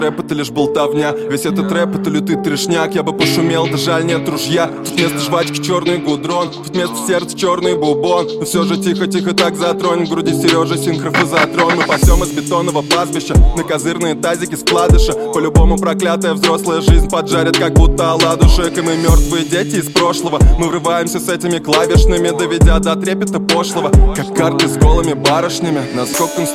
Музыка » Rap/Hip-Hop/R`n`B » Хип